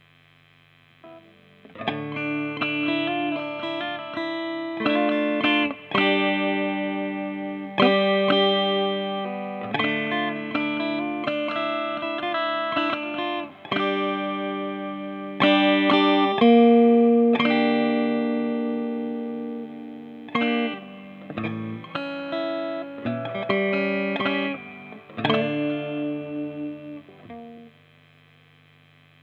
�������������� �� �����- ��� ������ ���������� - guitar.demo - ����� ����������